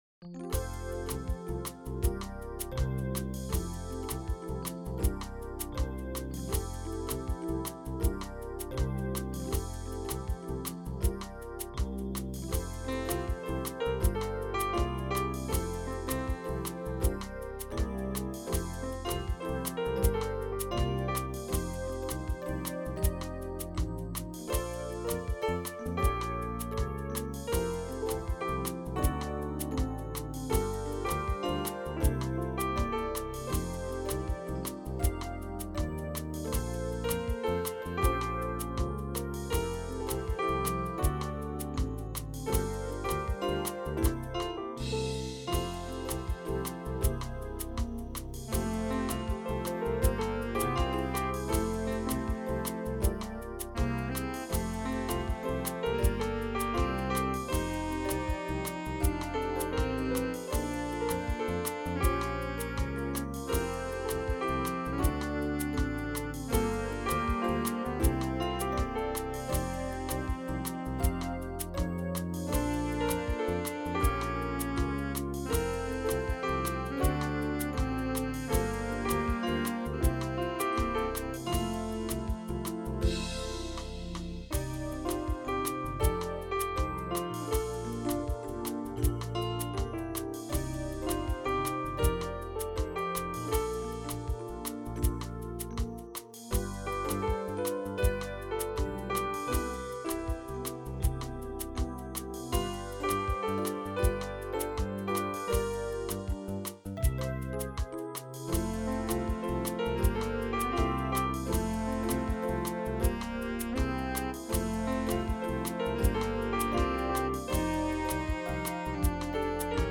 Piano in the lead